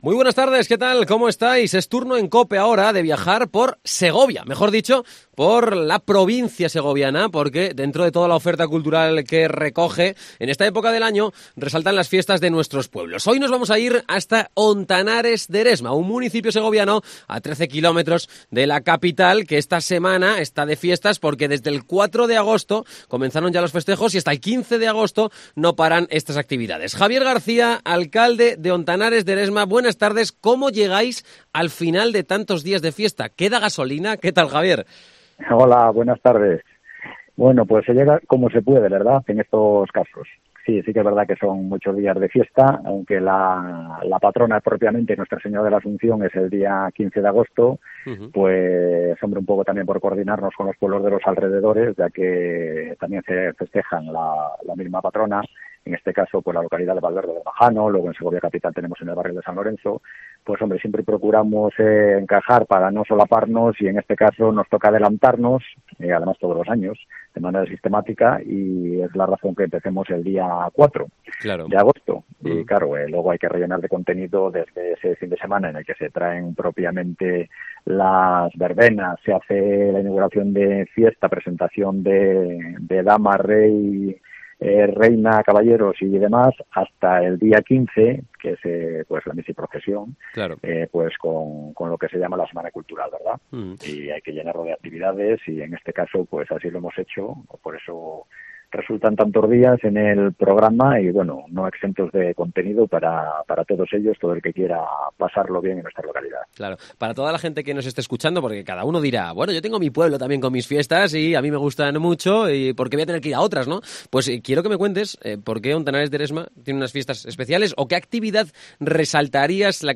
Entrevista a Javier García, Alcalde de Hontanares de Eresma.